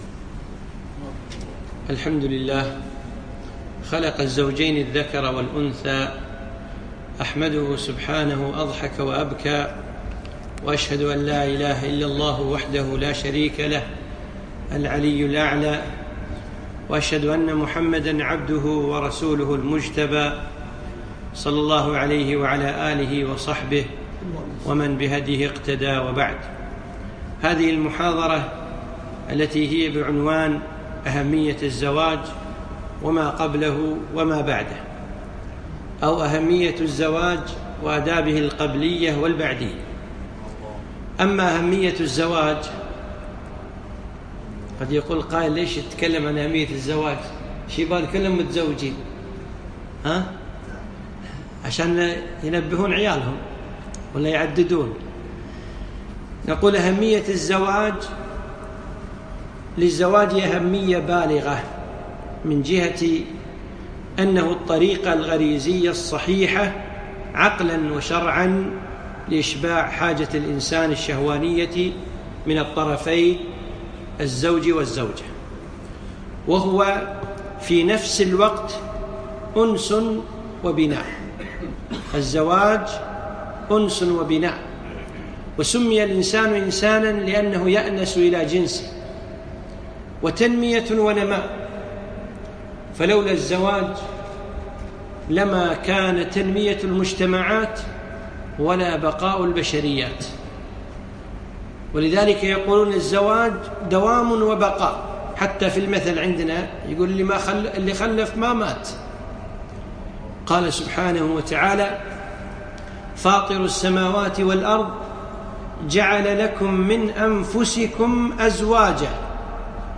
أهمية الزواج وما قبله ومابعده - محاضرة